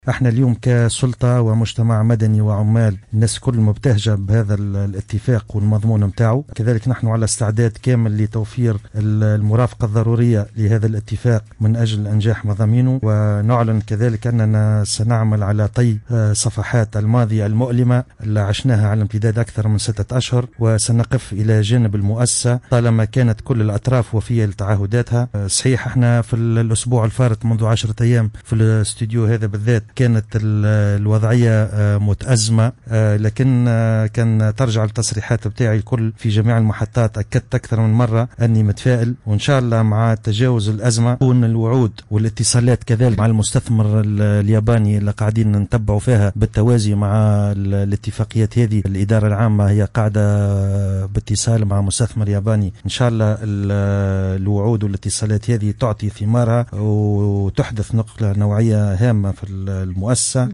تصريح معتمد مساكن